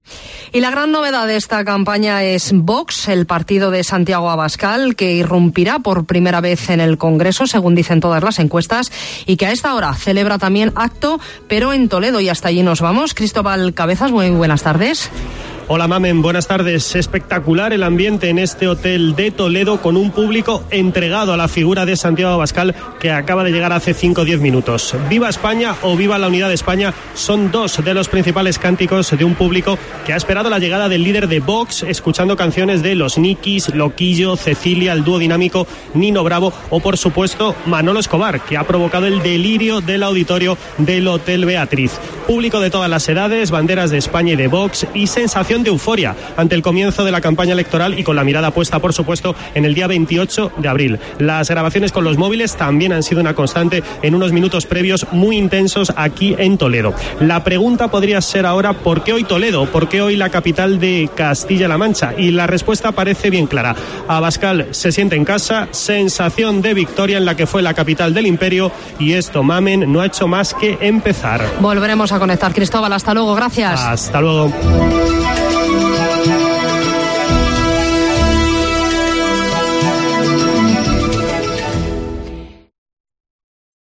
Así lo ha indicado durante un acto en Toledo